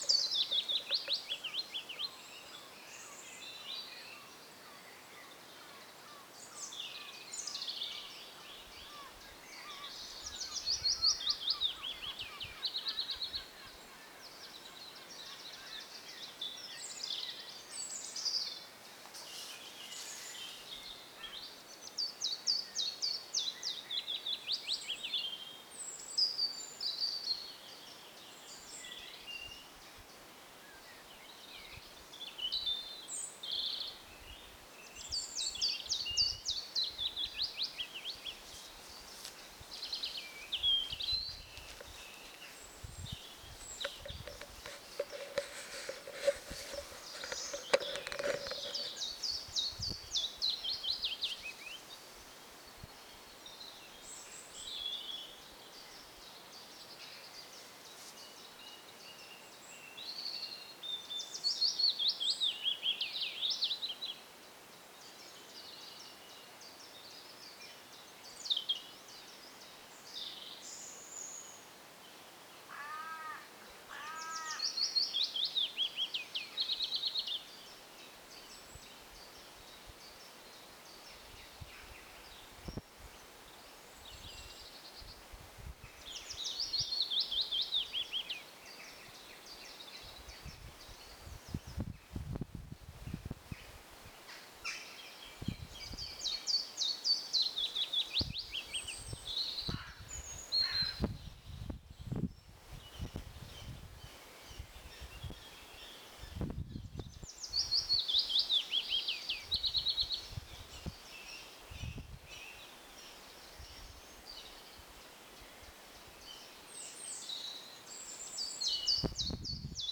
Example twelve. Many song-phrases beginning with Chiffchaff notes then merging seamlessly into Willow Warbler notes.
It also gave standard Willow Warbler phrases and occasionally phrases comprising only Chiffchaff notes, though of short duration and with a timbre and rhythm that were slightly awry compared with perfect Chiffchaff song.
here>, which comprises seven Willow Warbler phrases, six phrases switching from Chiffchaff to Willow Warbler and two phrases involving just Chiffchaff notes.